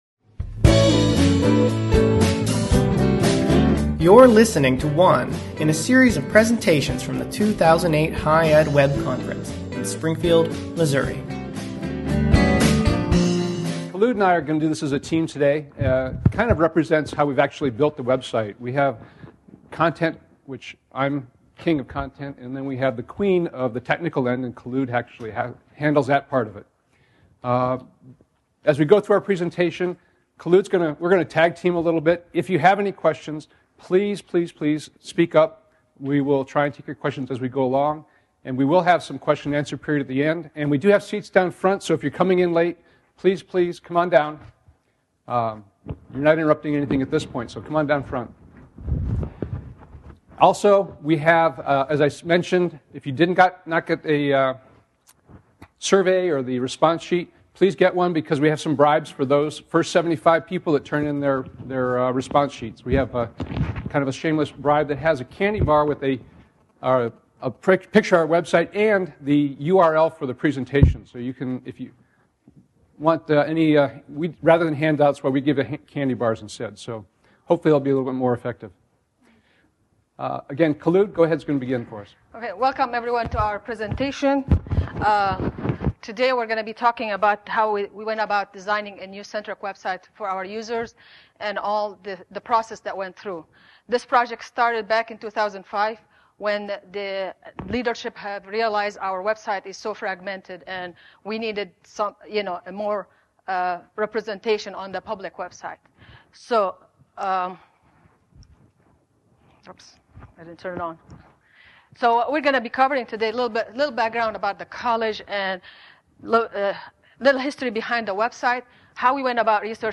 This poster session charts the principles, processes, and prototypes one needs in order to create a hybrid Web